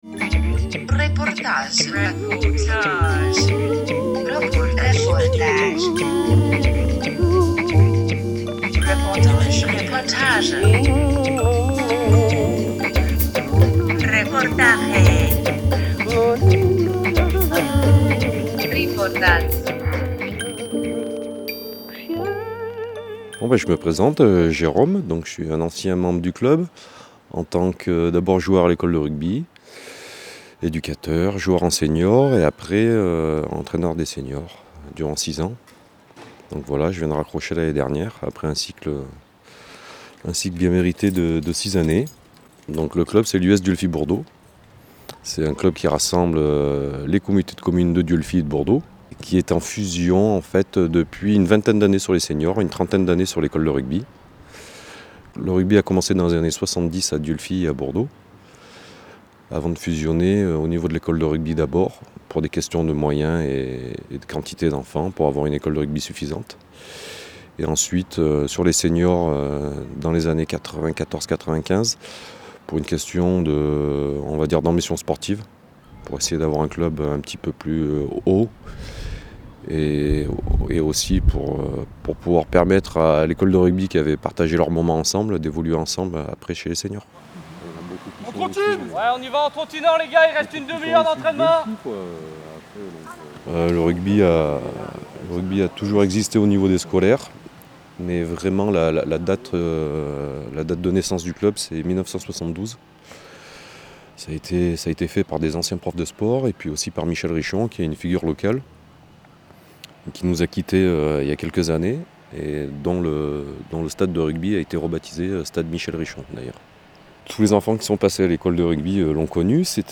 20 septembre 2017 15:13 | Interview, reportage